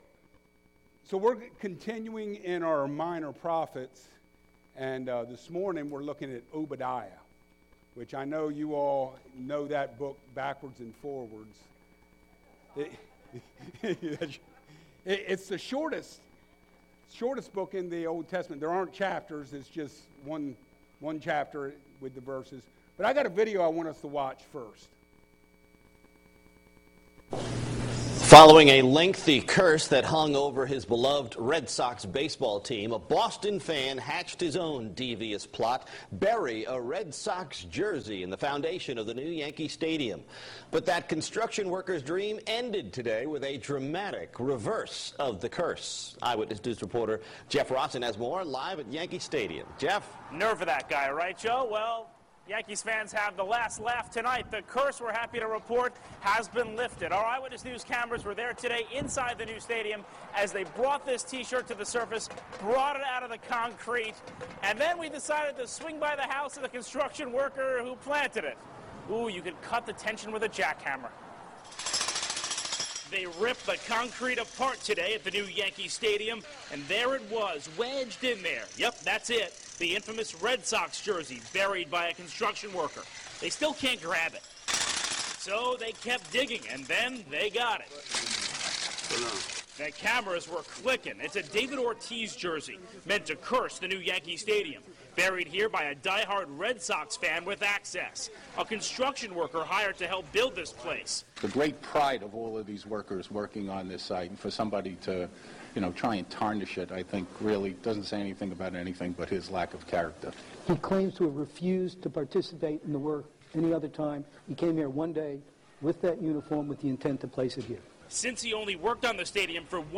Obadiah 3-15 Service Type: Sunday Mornings A Study of Old Testament Prophets